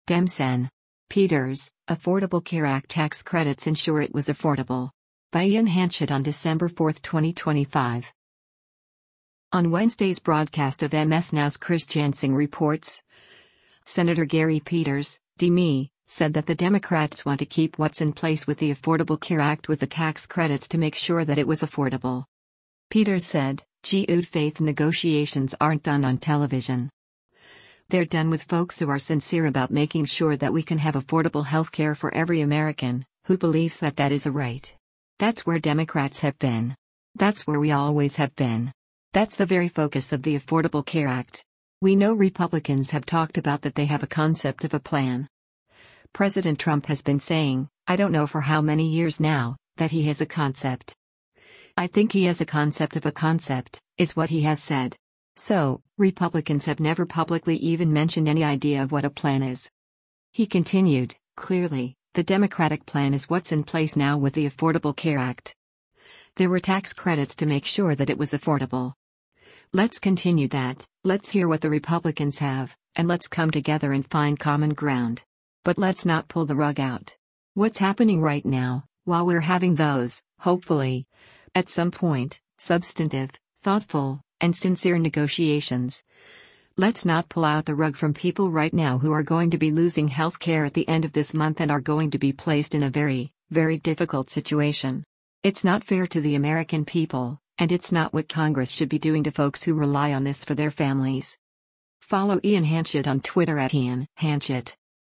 On Wednesday’s broadcast of MS NOW’s “Chris Jansing Reports,” Sen. Gary Peters (D-MI) said that the Democrats want to keep what’s in place with the Affordable Care Act with the “tax credits to make sure that it was affordable.”